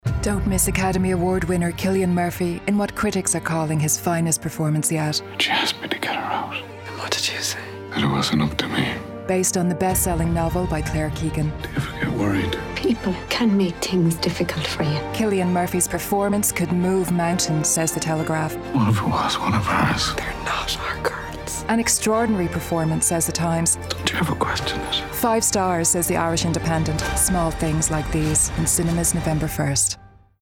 Promo, Dramatic, Smooth
Southern Irish